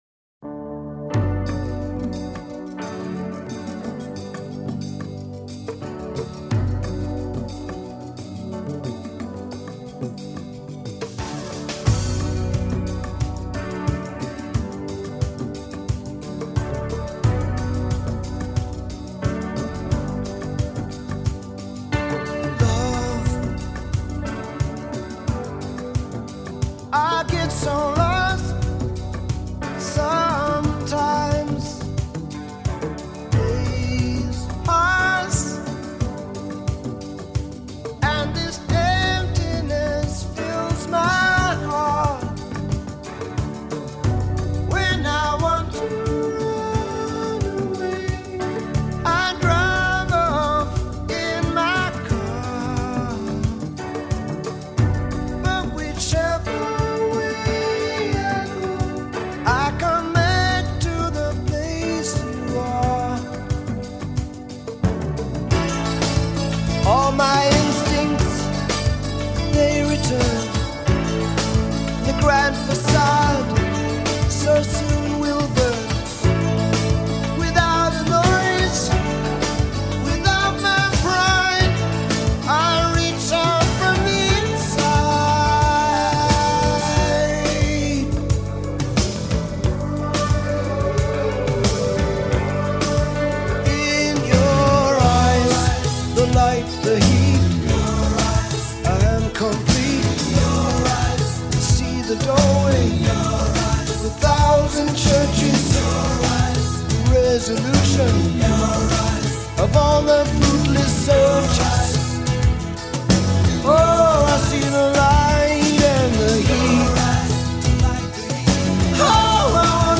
Enjoy a love song from the 80s.